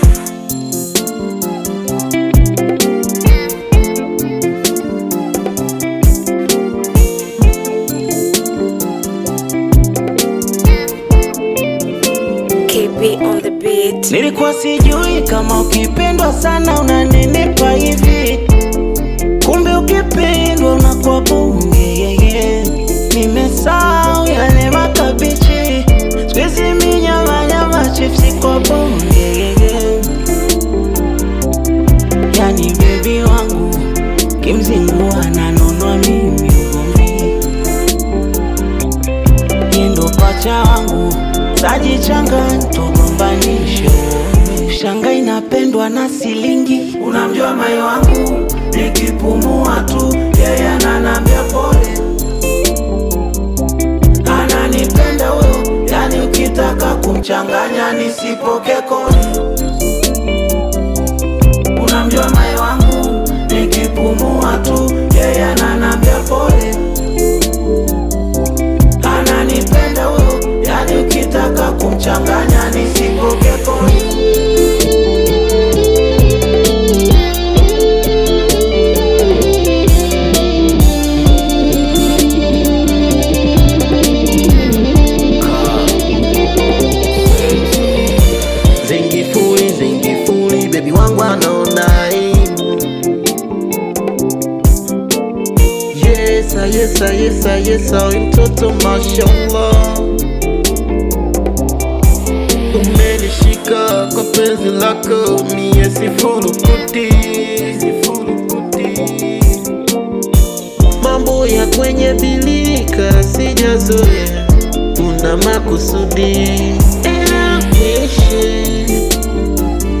smooth Zouk–Bongo Flava fusion
soulful voice
melodic flow, creating a warm, catchy, and passionate vibe.